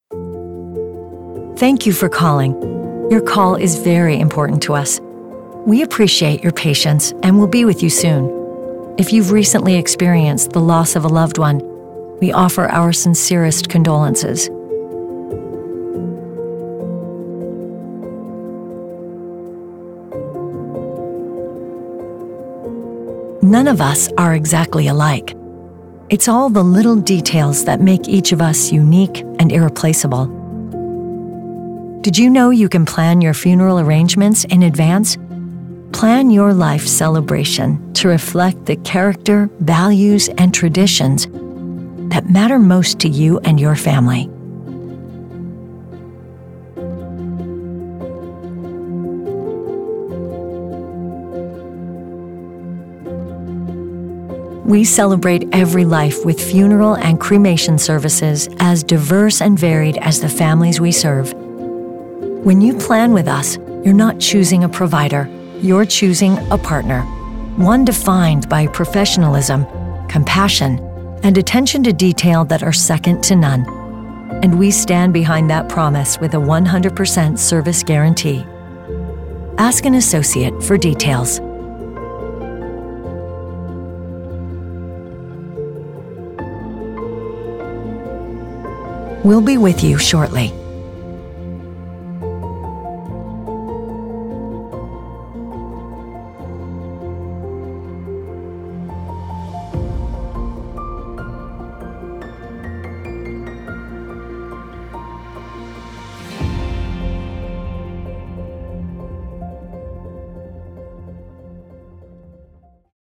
Dignity Memorial On-Hold Message
on-hold-message-funeral-wav